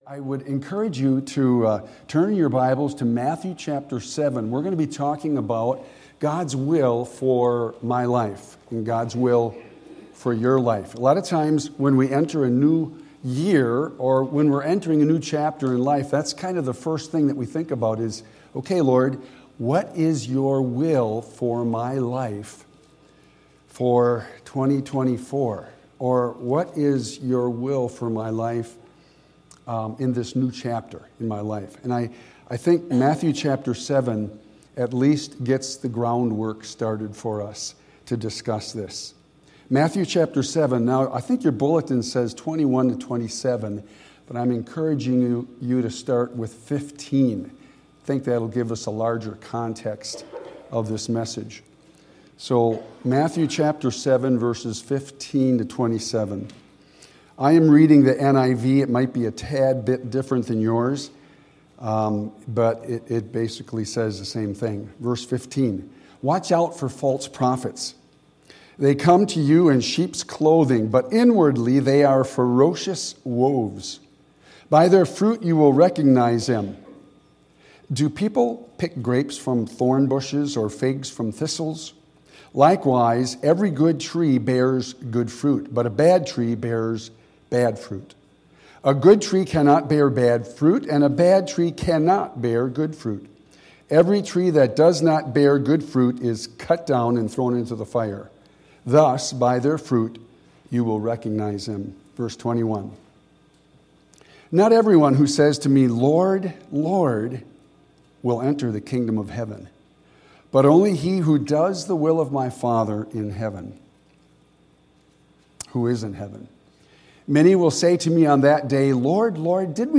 What is God’s will for your life in 2024 and beyond? This sermon looks at 5 divine wills for every child of God.